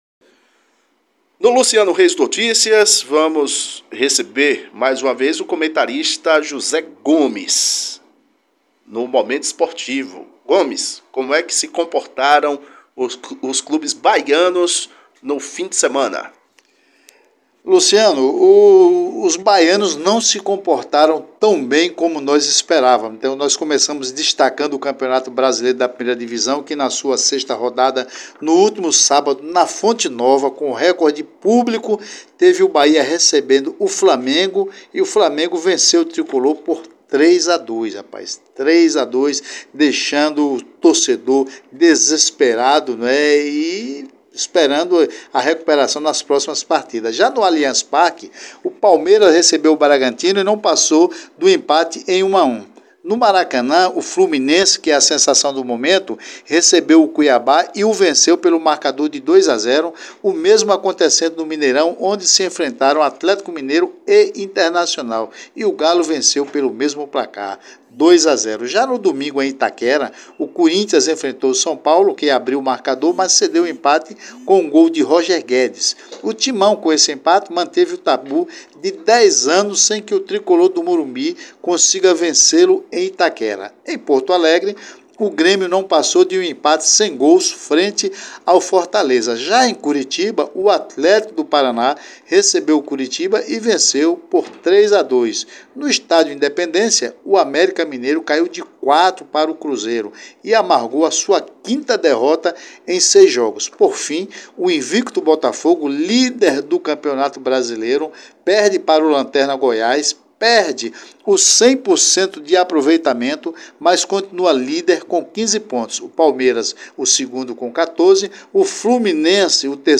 o comentarista esportivo